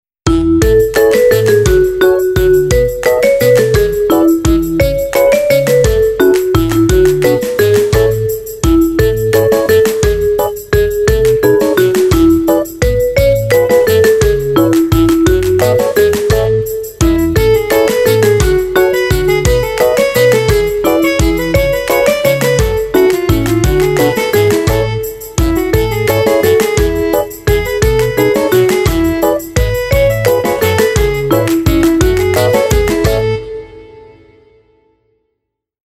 Mit 24 beschwingten Weihnachtssongs